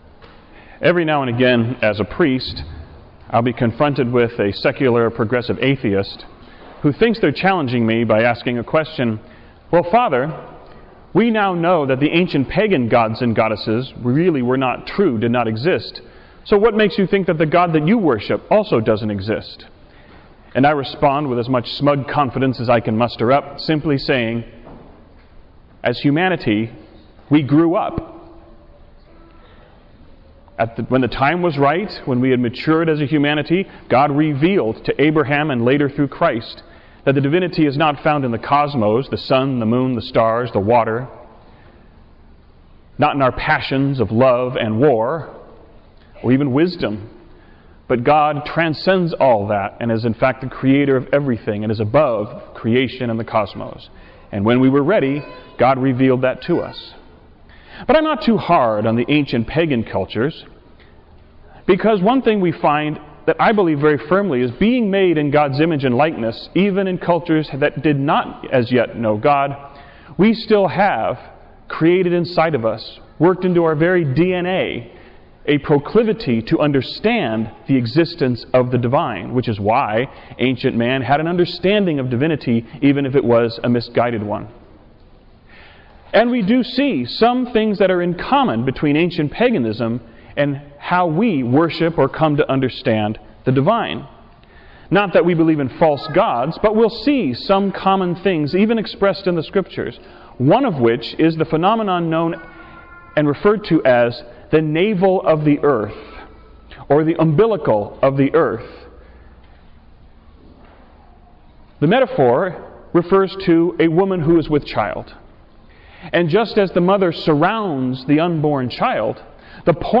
homily-CORPUSCHRISTI20173.wav